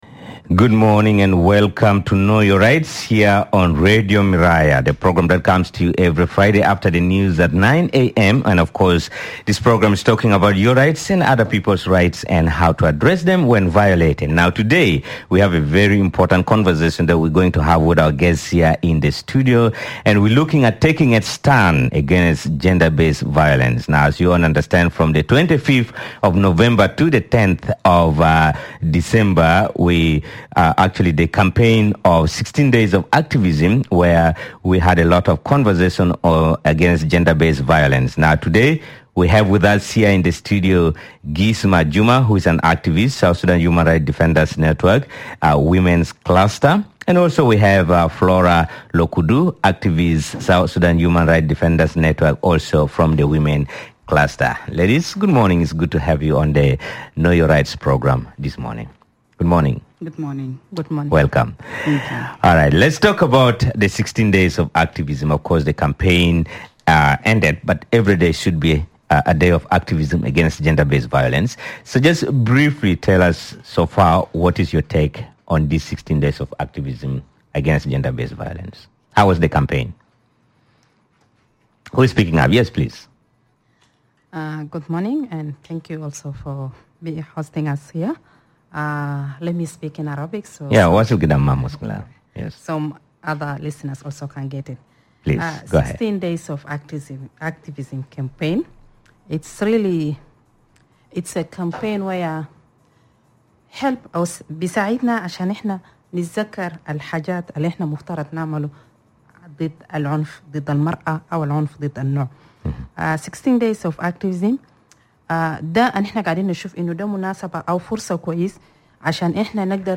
two panelists